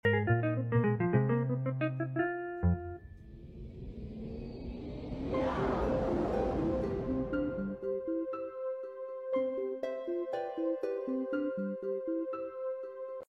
Plants Vs Zombies Cherry Bomb Sound Effects Free Download